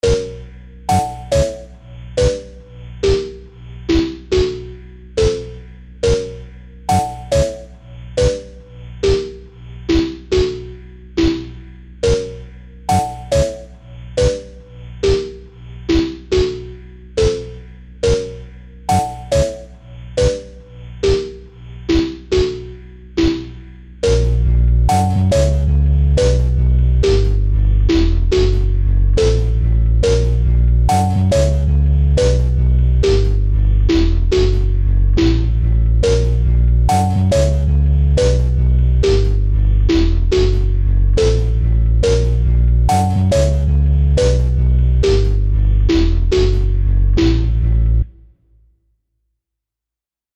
- Piano Music, Solo Keyboard - Young Composers Music Forum